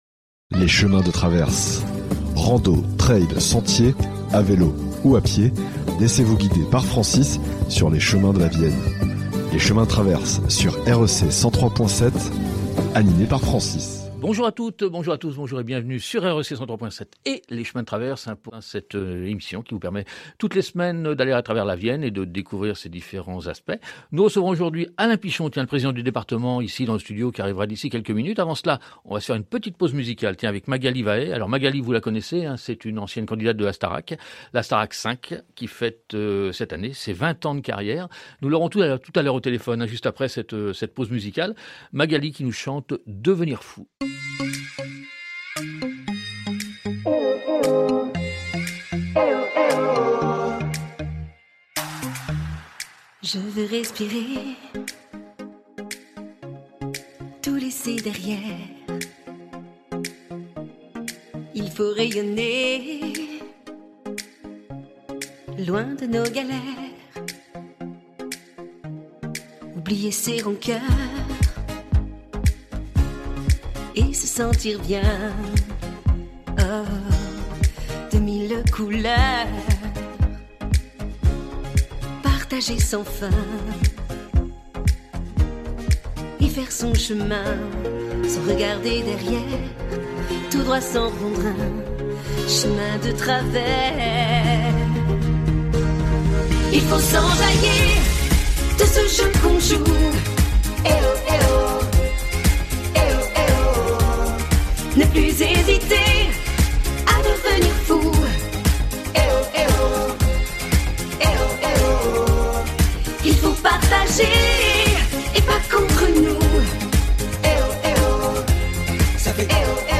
Le quart d’heure Poitevin – Interview de Laurence Vallois-Rouet – Conseillère régionale-